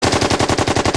submachinegun.mp3